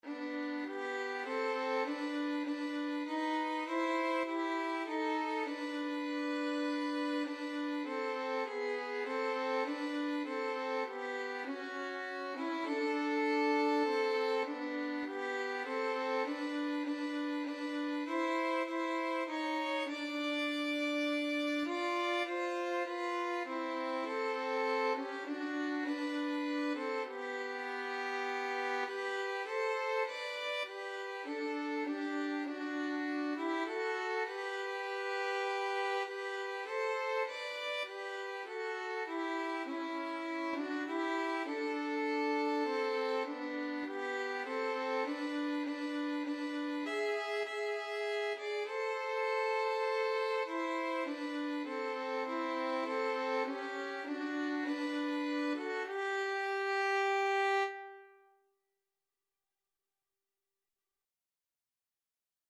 3/4 (View more 3/4 Music)
Classical (View more Classical Violin Duet Music)